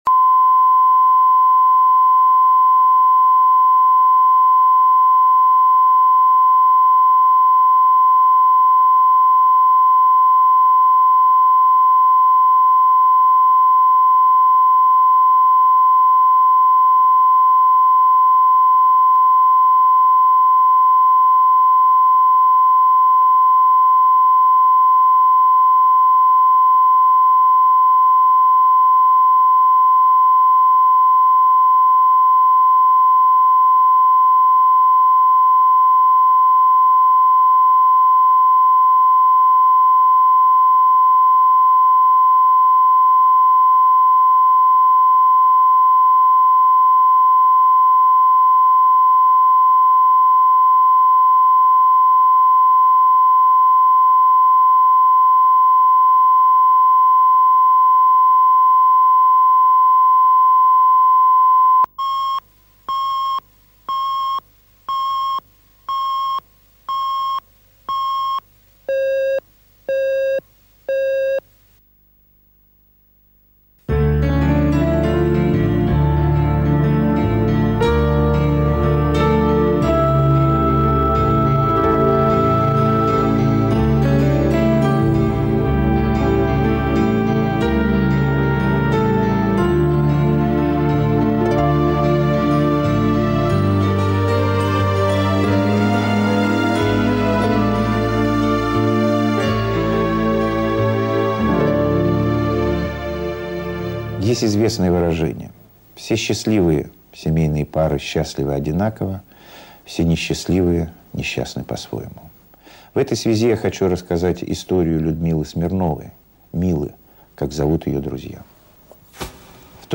Аудиокнига Семь лет в браке | Библиотека аудиокниг